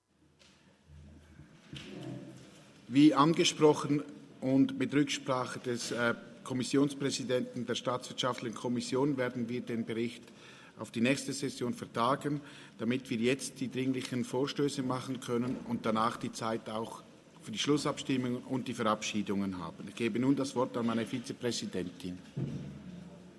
Jäger-Vilters-Wangs, Ratspräsident, stellt Kenntnisnahme von der Berichterstattung 2022 der Vertretung des Kantonsrates in der Internationalen Parlamentarischen Bodensee-Konferenz (Frühjahrstagung) fest.
Session des Kantonsrates vom 19. bis 21. September 2022